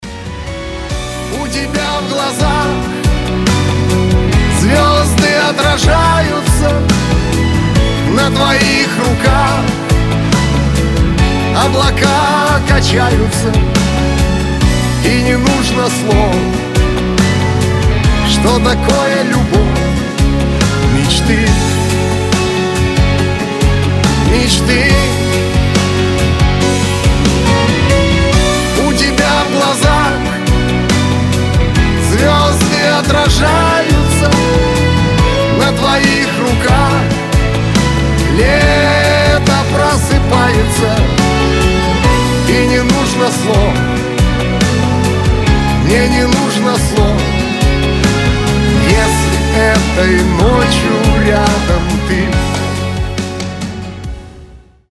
красивые
лирика
душевные